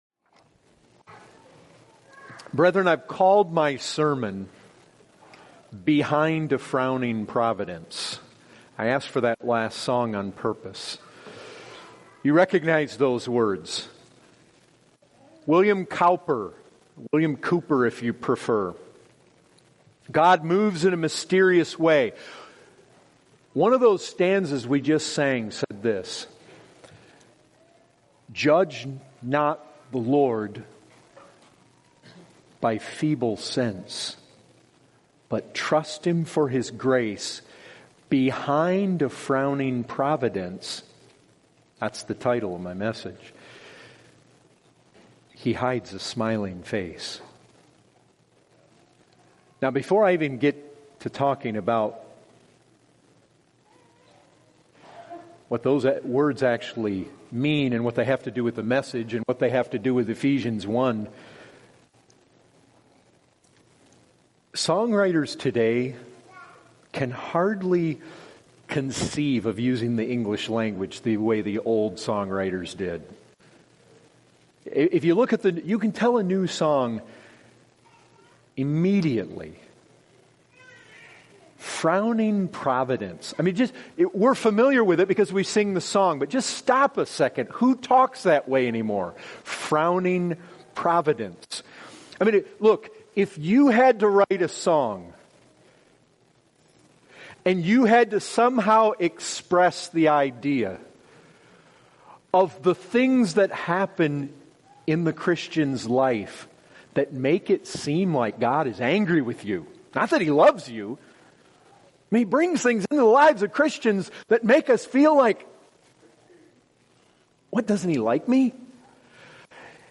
2017 Category: Full Sermons Topic